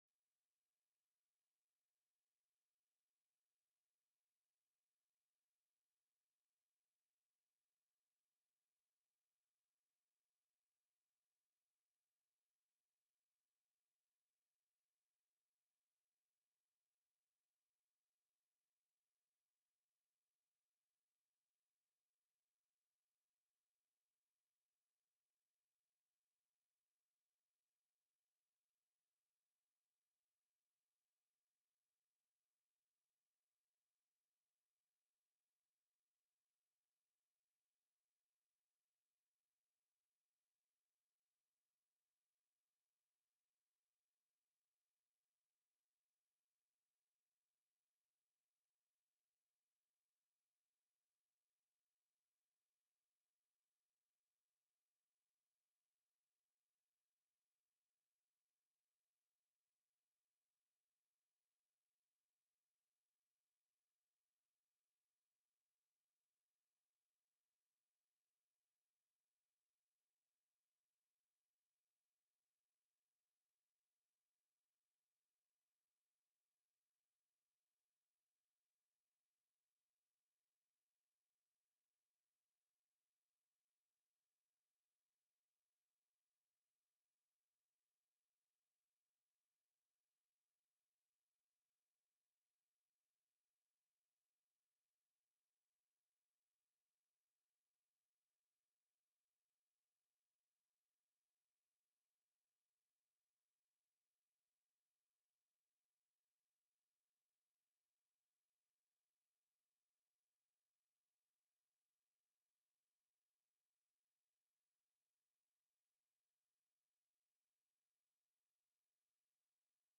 The audio recordings are captured by our records offices as the official record of the meeting and will have more accurate timestamps.
HB 263 APPROP: OPERATING BUDGET;AMEND;SUPP TELECONFERENCED